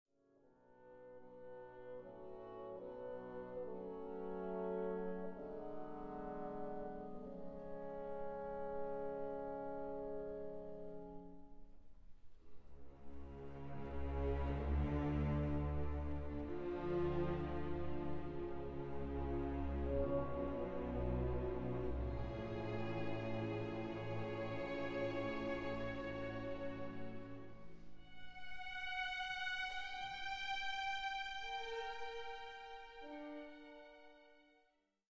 Fantasy Overture